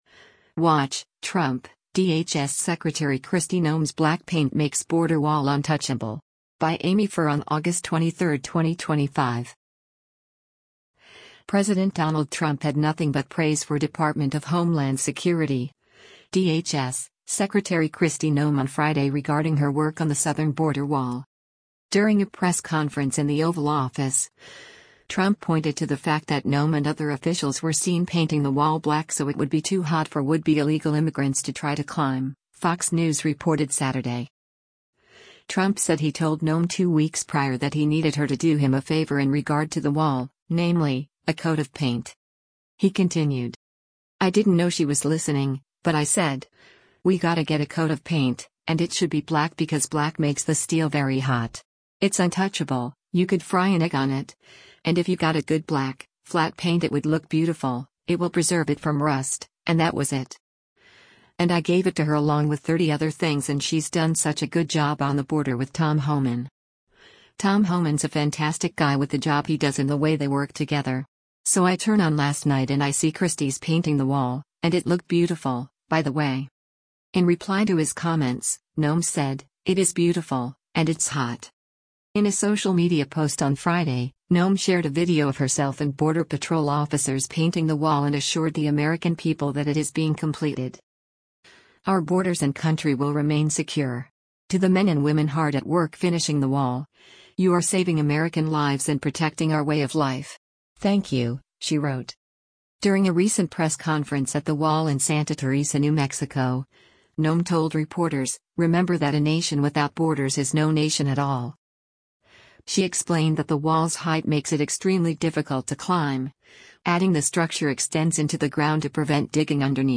During a press conference in the Oval Office, Trump pointed to the fact that Noem and other officials were seen painting the wall black so it would be too hot for would-be illegal immigrants to try to climb, Fox News reported Saturday.